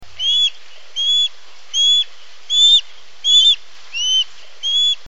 Balbuzard pêcheur
Pandion haliaetus
balbuzard.mp3